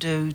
seeding-planets/dialogs/characters/typing_sounds/sigourney_weaver.wav